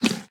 latest / assets / minecraft / sounds / mob / llama / eat3.ogg
eat3.ogg